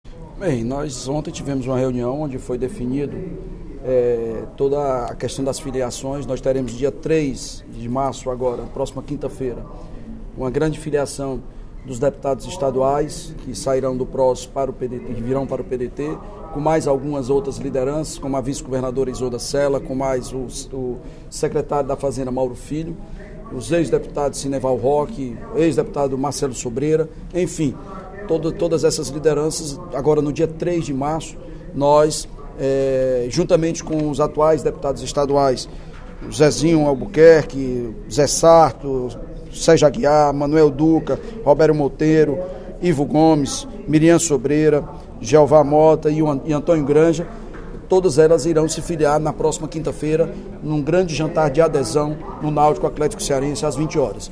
O líder do Governo, deputado Evandro Leitão (PDT), anunciou, nesta sexta-feira (26/02), durante o primeiro expediente da sessão plenária, que, no próximo dia 3 de março, às 20h, será realizada, em Fortaleza, a filiação de grandes lideranças ao PDT.